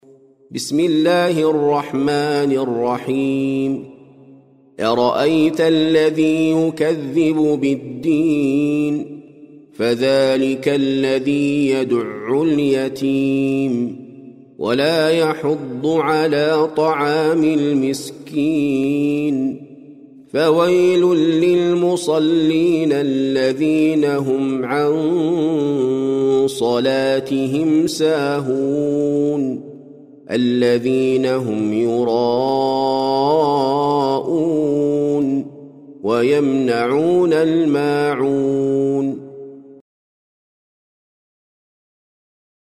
سورة الماعون - المصحف المرتل (برواية حفص عن عاصم)